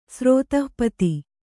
♪ srōtah pati